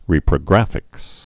(rēprə-grăfĭks)